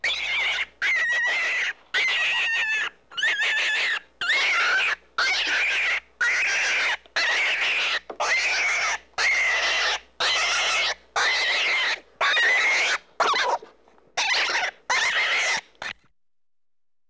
animal
New Zealand White Rabbit Squealing